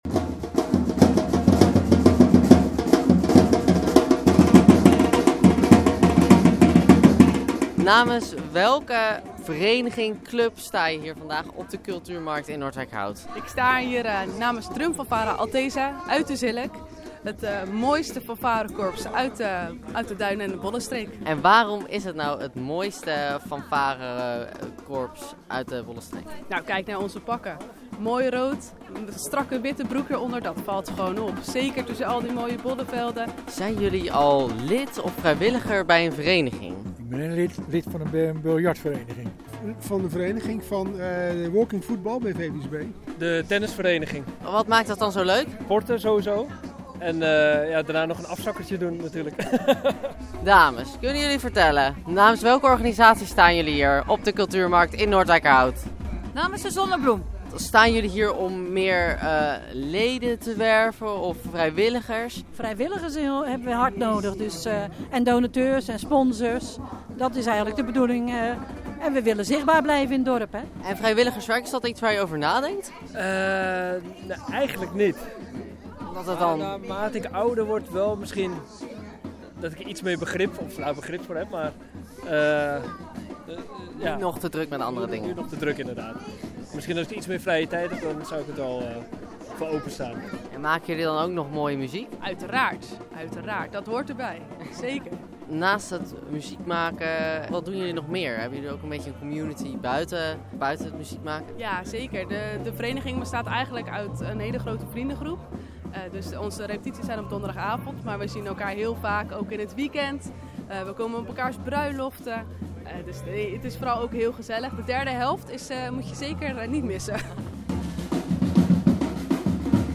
Noordwijkerhout – Ondanks het wisselvallige weer is het deze week de beurt aan de verenigingen en organisaties in Noordwijkerhout om alles uit de kast te trekken en zichzelf op de kaart te zetten tijdens de cultuurmarkt rond de Witte Kerk.
‘Cultuur inspireert, verbindt, maar het geeft ons ook ontzettend veel plezier en we genieten er ongelofelijk van’, geeft wethouder Roberto Ter Hark mee in zijn speech.
Verslaggever